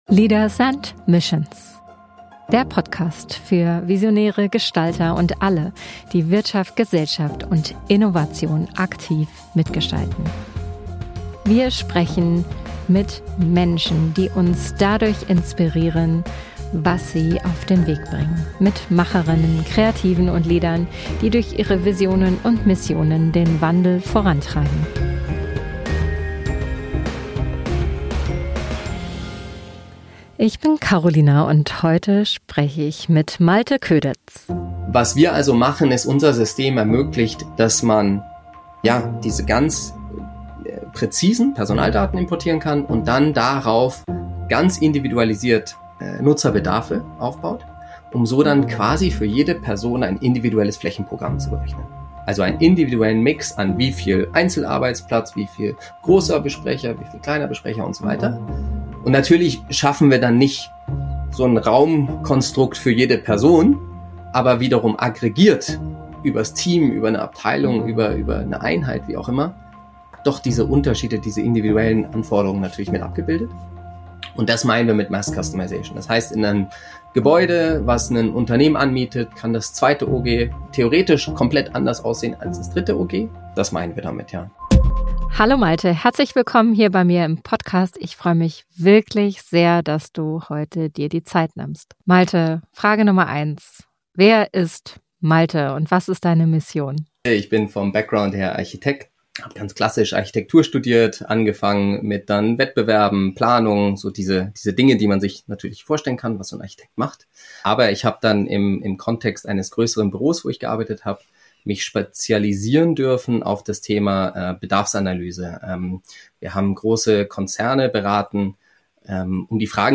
Ein Gespräch über die Zukunft der Arbeitswelten, die Rolle von Real Estate im Unternehmen und darüber, warum die besten Entscheidungen auf fundierten Daten basieren.